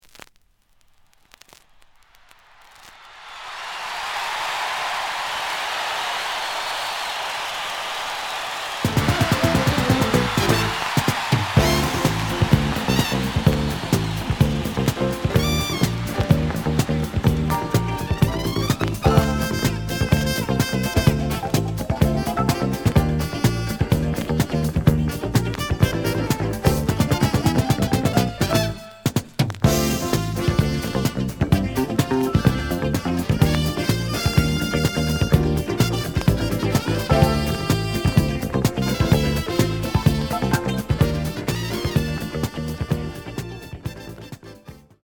The audio sample is recorded from the actual item.
●Genre: Disco
Some noise on beginning of both sides due to scratches.)